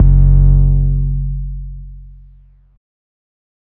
TC 808 4.wav